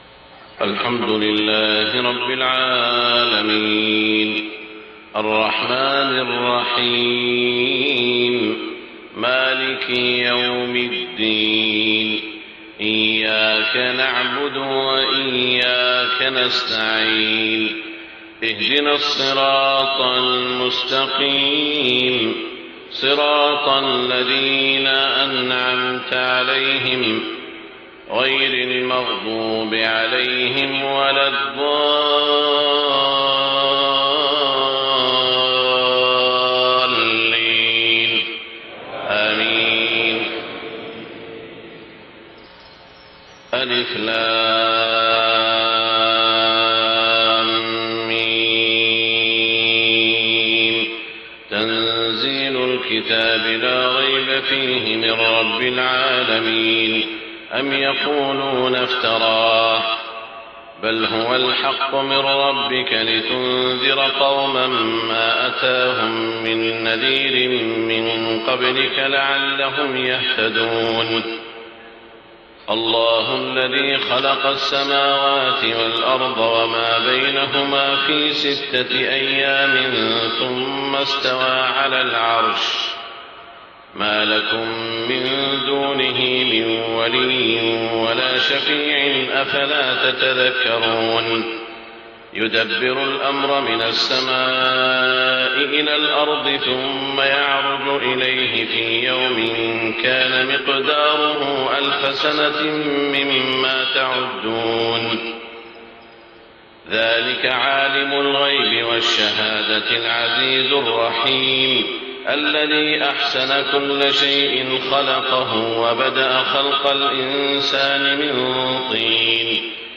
صلاة الفجر 23 ربيع الأول 1430هـ سورتي السجدة و الإنسان > 1430 🕋 > الفروض - تلاوات الحرمين